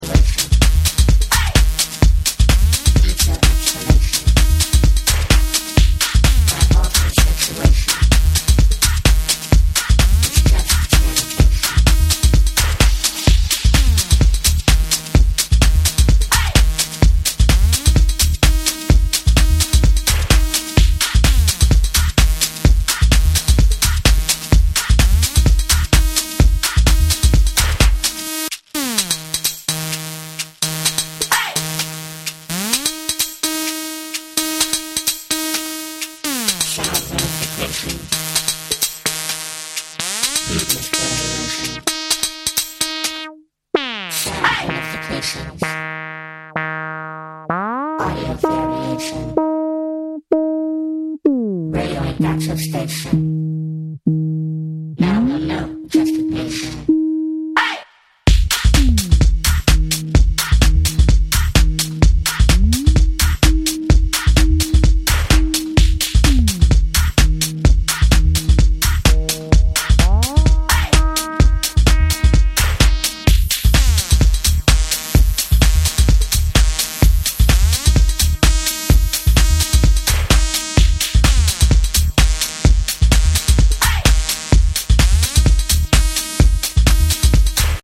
the electronic scene